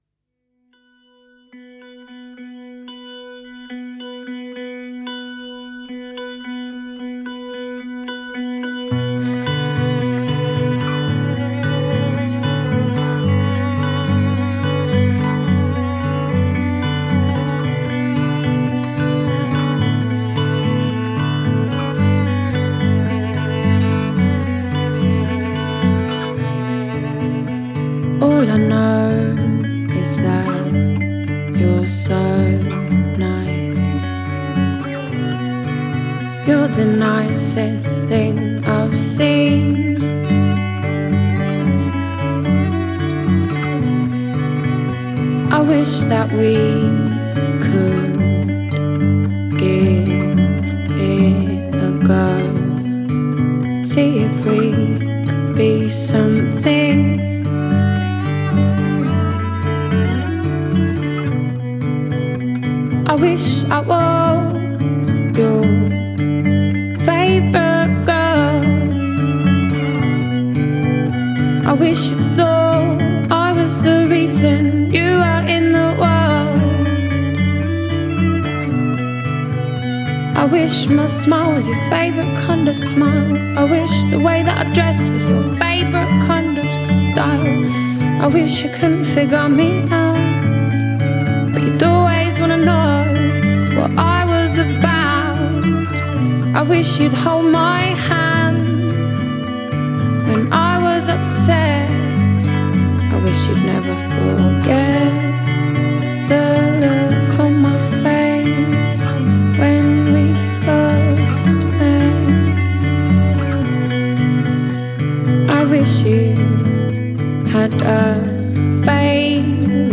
Today’s Emo